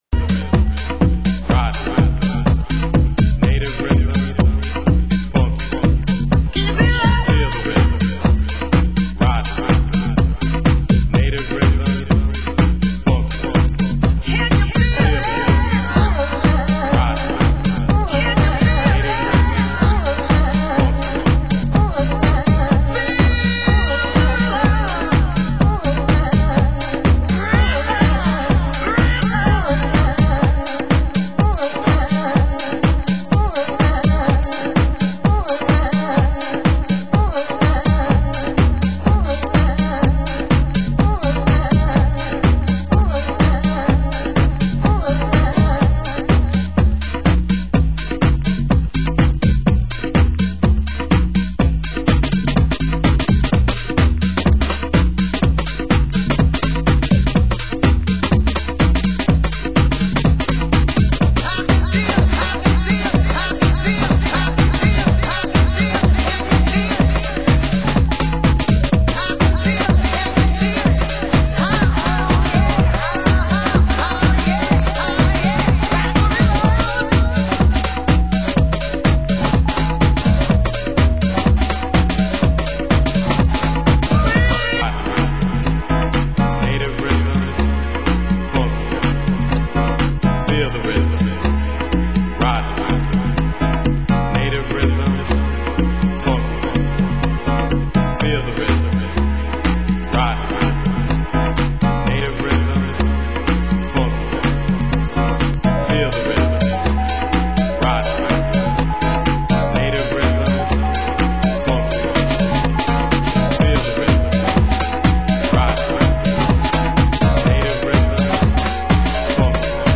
US House